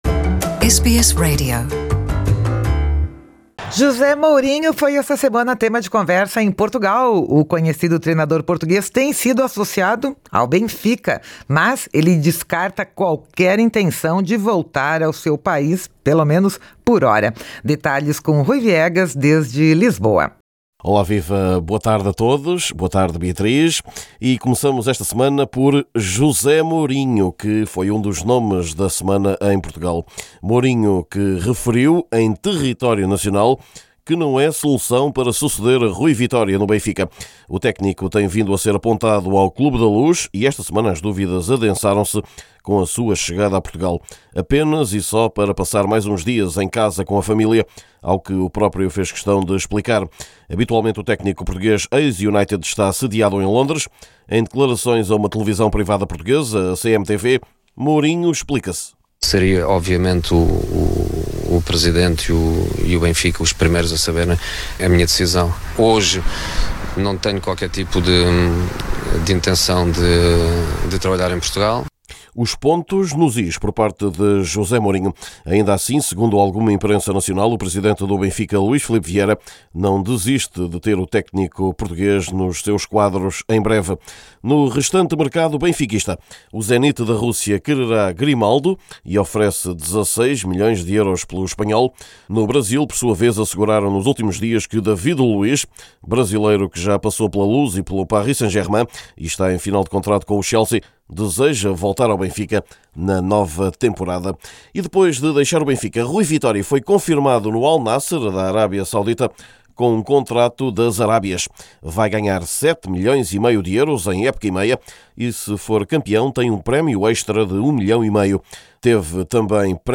Neste boletim falamos ainda do mercado ou de mais um caso de problemas financeiros num clube do futebol luso.